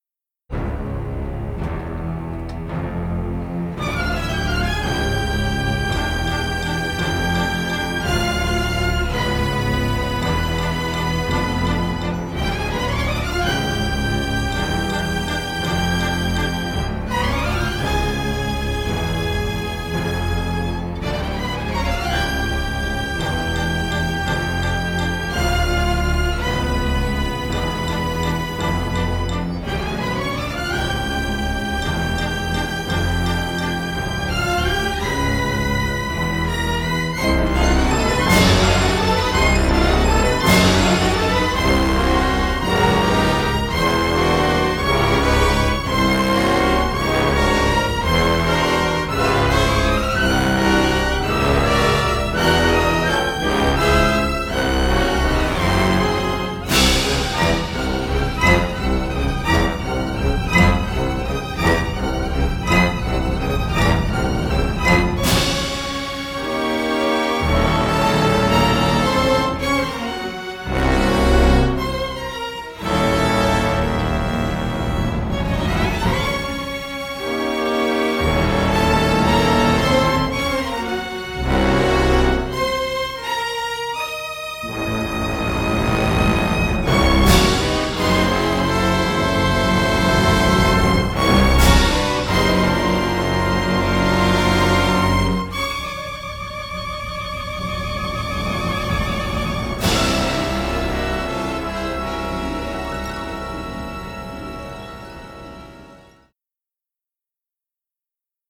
Dynamic orchestral score